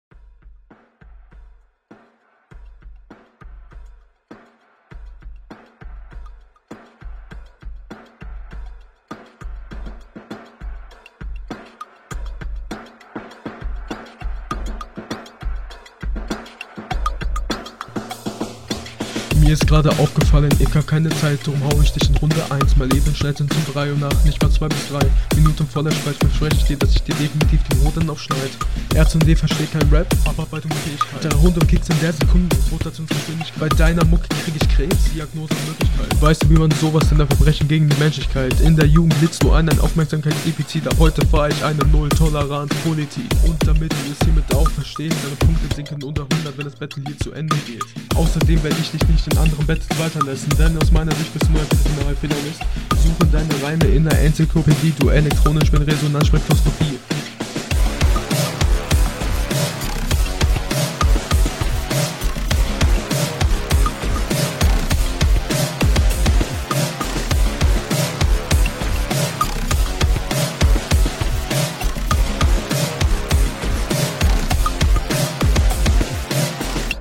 Verstehe kaum etwas vom Text. Bitte mehr an der Mische/dem Mastering arbeiten.